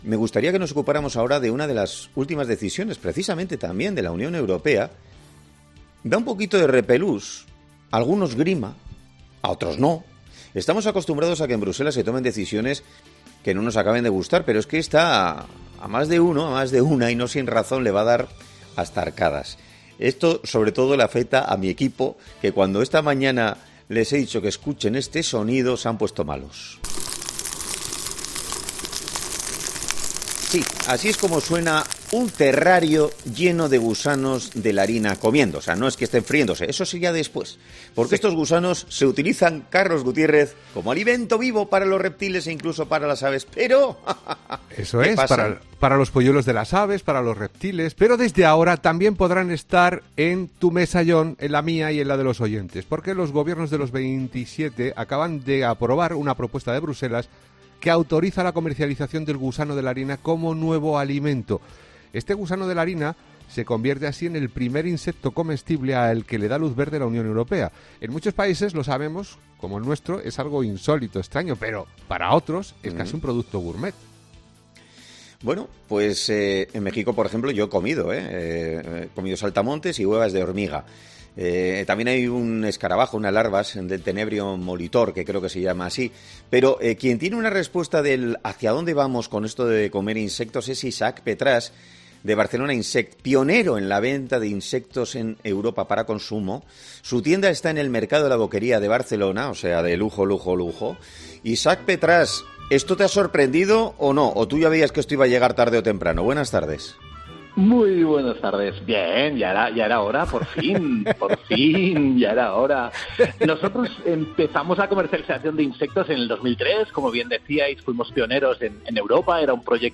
Este jueves en 'Herrera en COPE' ha sido entrevistado